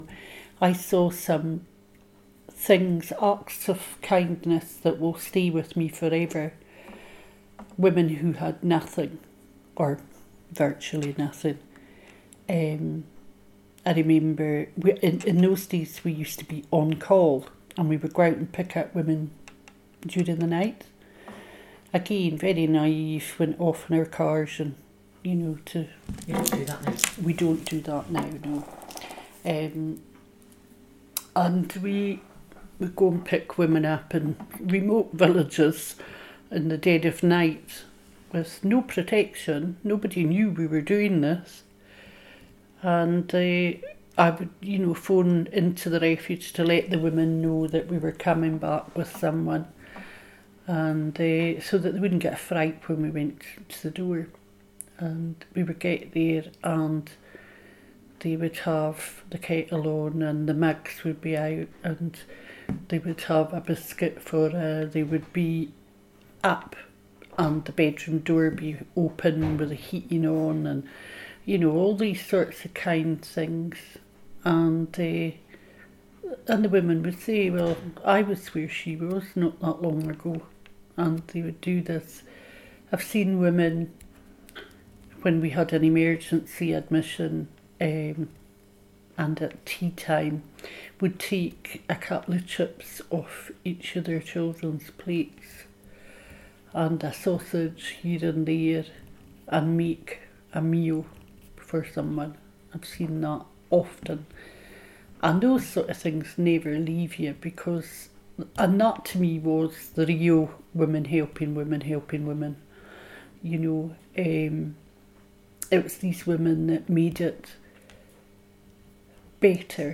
Speaking Out: Oral history clips
The Speaking Out project is gathering oral history interviews with women connected to the Women’s Aid movement, both past and present.